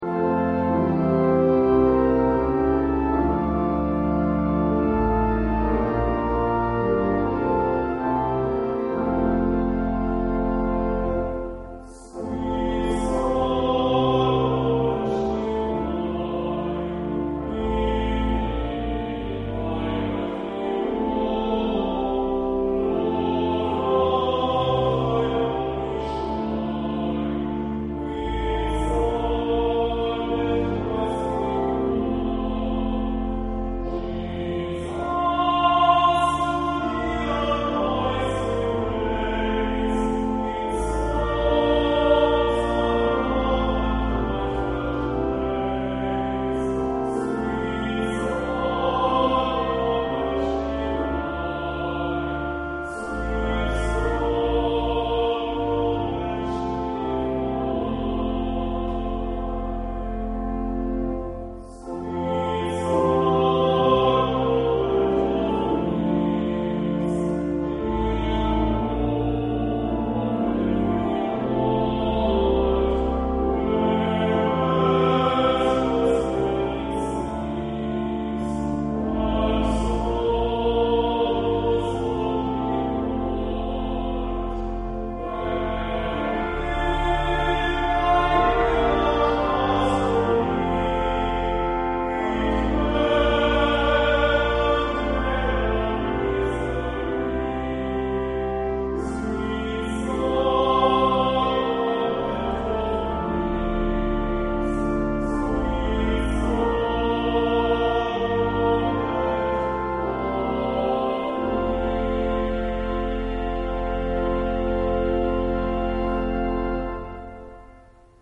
Sweet Sacrament Divine (with Pipe Organ)
Sweet-Sacrament-Divine-with-Pipe-Organ.mp3